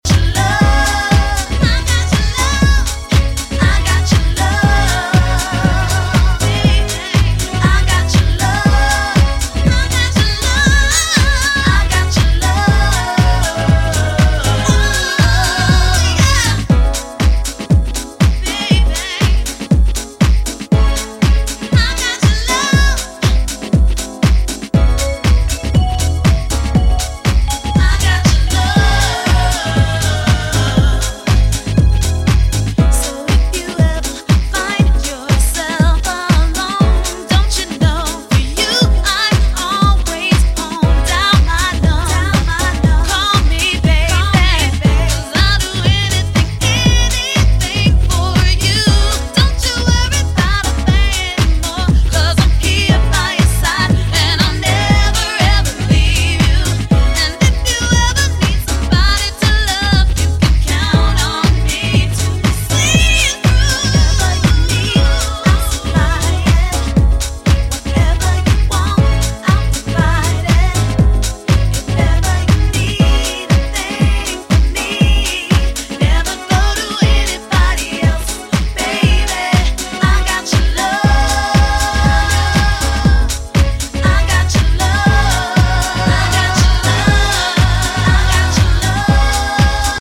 ジャンル(スタイル) DEEP HOUSE / GARAGE HOUSE